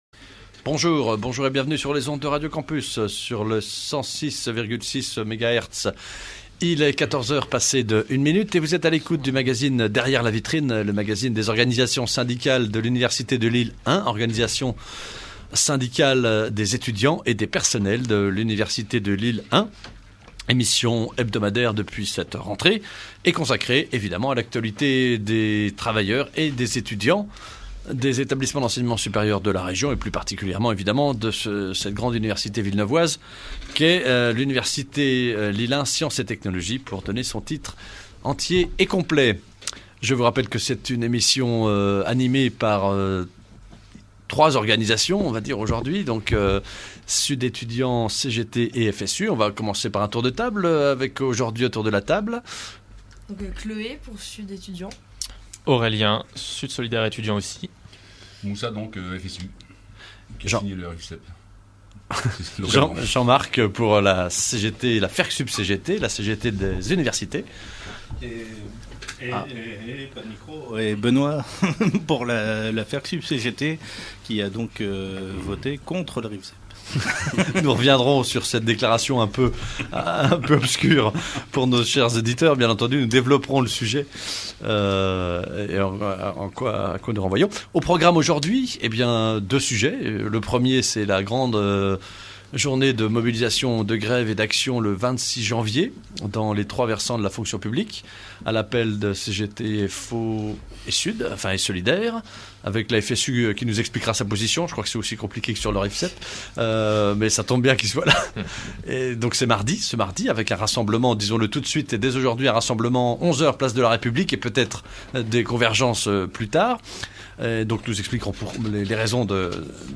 « Derrière la Vitrine », c’est l’émission des syndicats (étudiant-e-s et personnels) de l’université Lille1, sur Radio Campus Lille (106,6 FM), tous les jeudis, de 14h à 15h. On y parle de l’actualité universitaire et des luttes sociales.